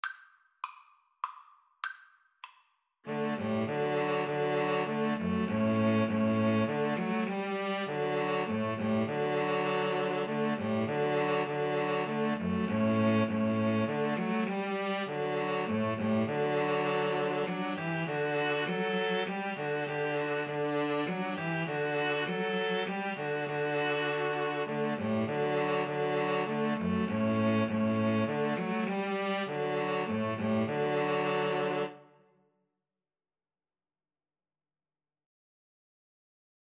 D major (Sounding Pitch) (View more D major Music for String trio )
Classical (View more Classical String trio Music)